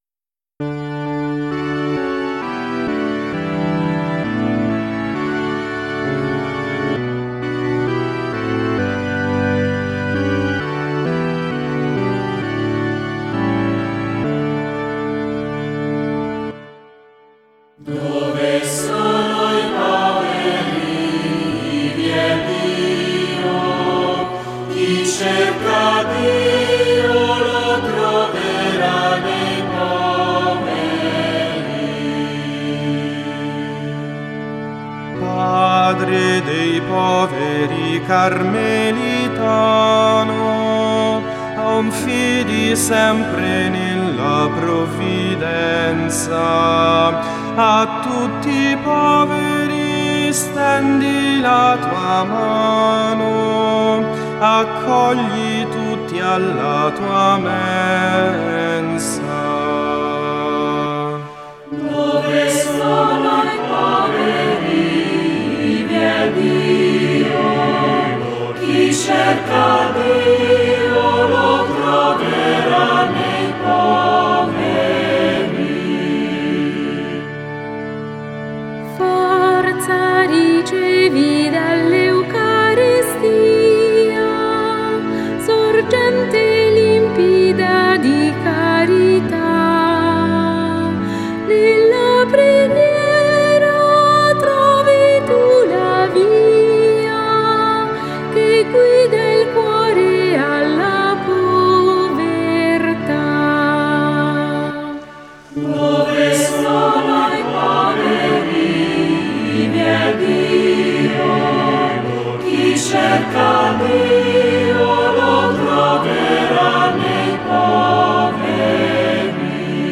[ leggi ] Inno " Dove sono i poveri, ivi è Dio ", cantato dai Piccoli Cantori di Torre Spaccata.